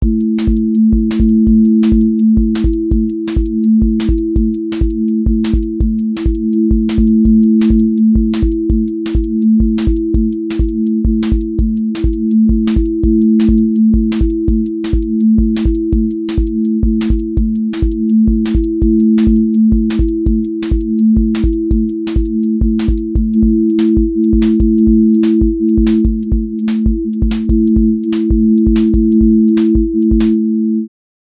dnb break pressure
QA Listening Test drum-and-bass Template: dnb_break_pressure
• voice_kick_808
• voice_snare_boom_bap
• voice_hat_rimshot
• voice_sub_pulse
• tone_brittle_edge
• fx_drum_bus_glue